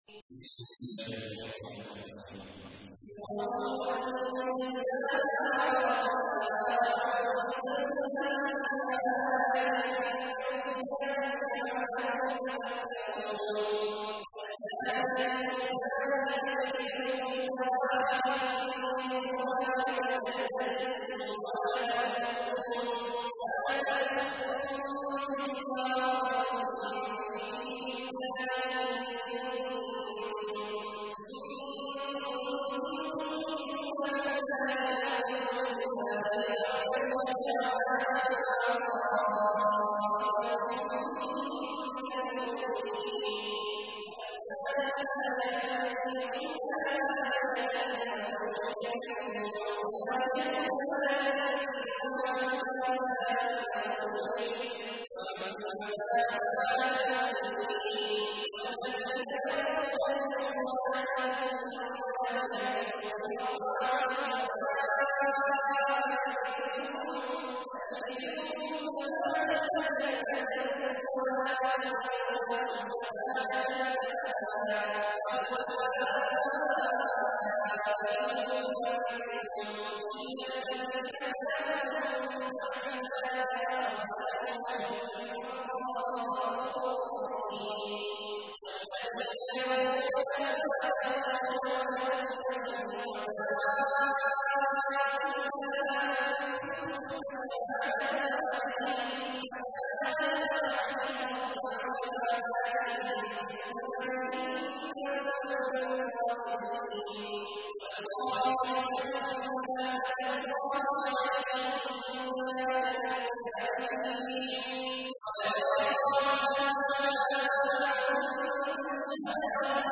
تحميل : 24. سورة النور / القارئ عبد الله عواد الجهني / القرآن الكريم / موقع يا حسين